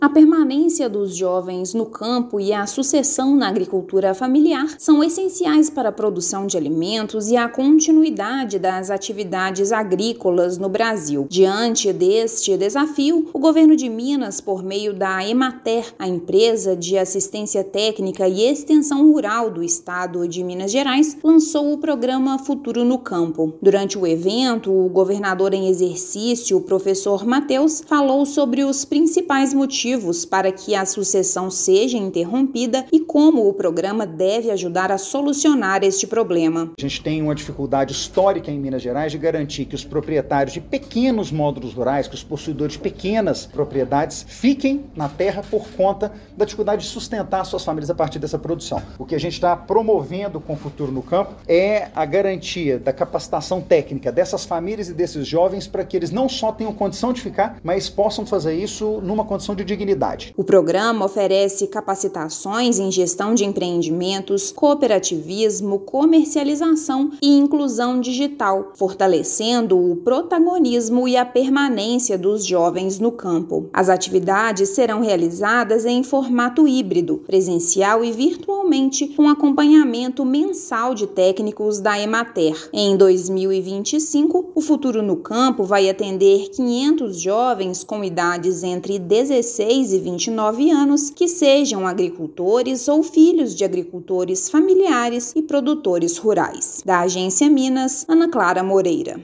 Iniciativa da Empresa de Assistência Técnica e Extensão Rural do Estado de Minas Gerais (Emater-MG) vai beneficiar 500 jovens em 2025, com capacitações e ações de estímulo à permanência nas atividades do campo. Ouça matéria de rádio.